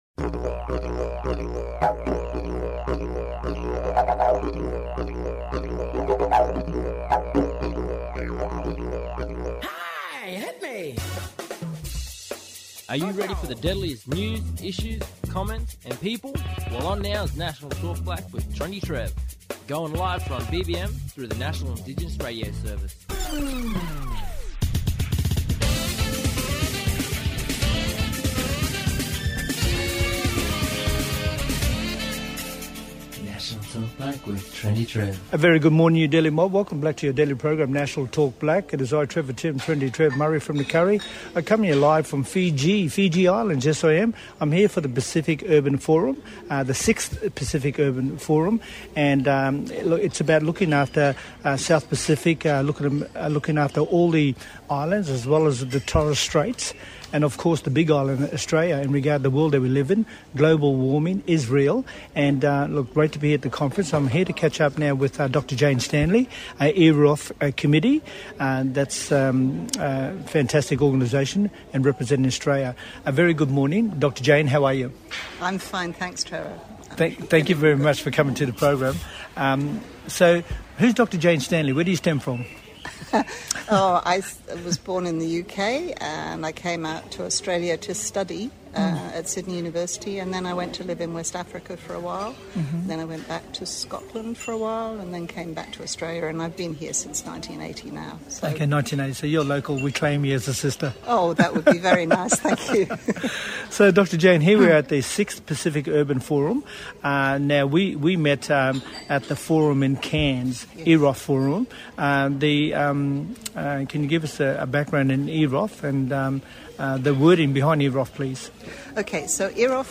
From the 6th Pacific Urban Forum in Fiji;